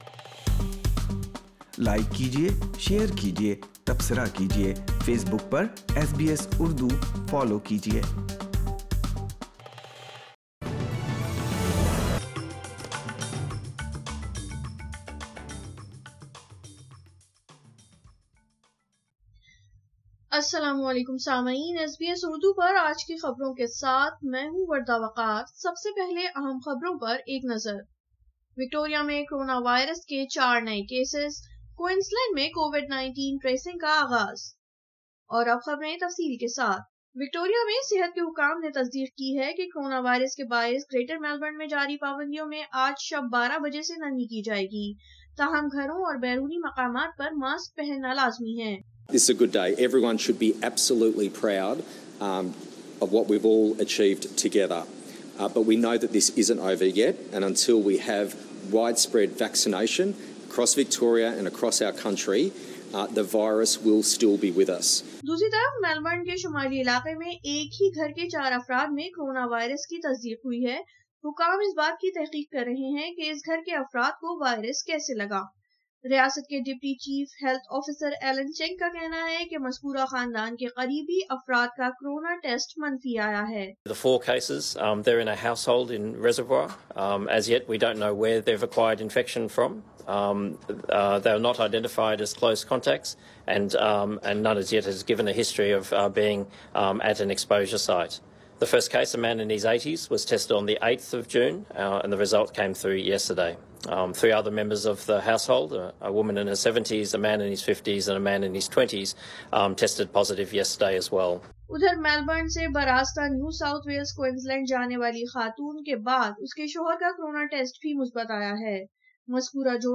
SBS Urdu News 10 june 2021